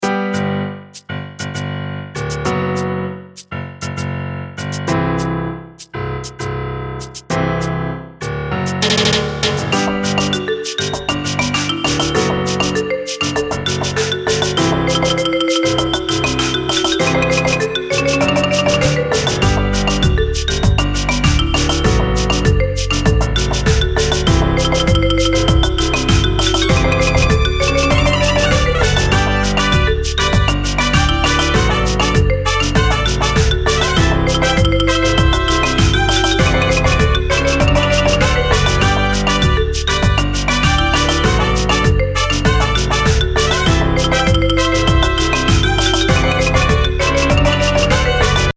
Lets get funky Mexico style!